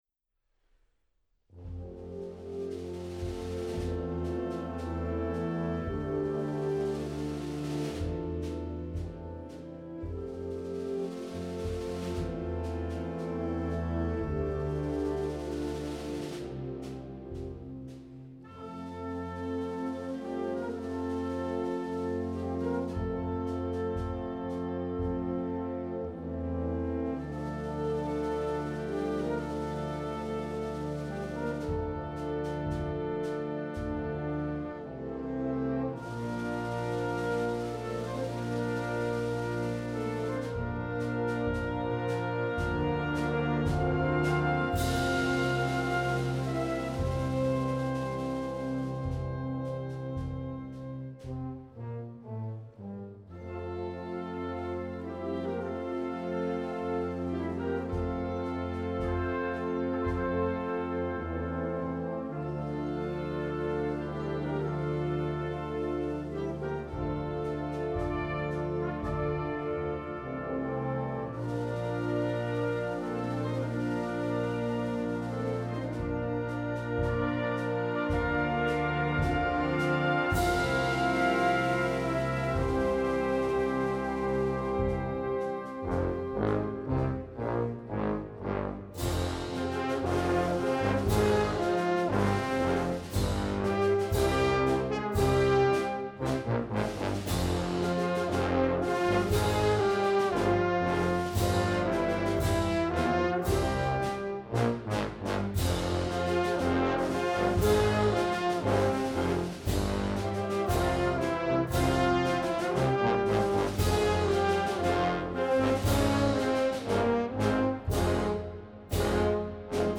Gattung: Trauermarsch
Besetzung: Blasorchester